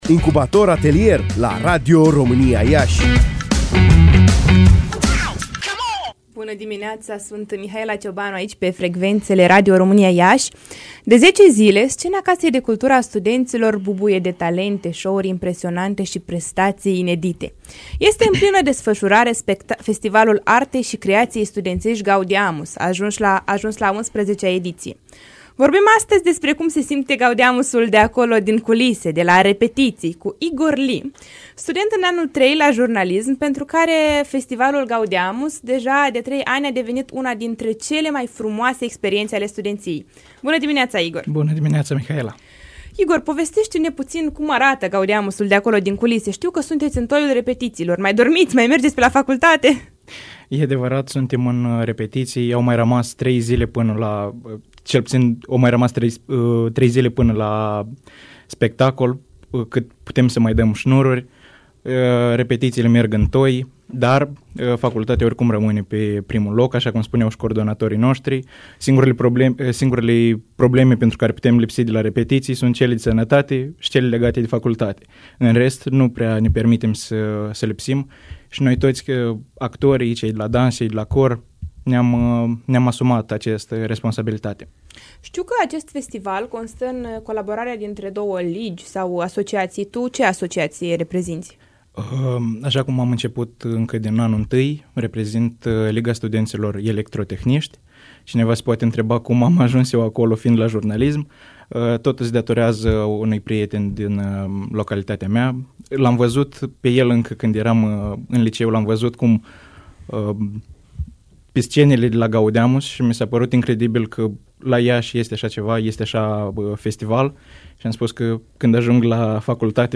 Incubator Atelier la Radio România Iași